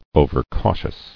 [o·ver·cau·tious]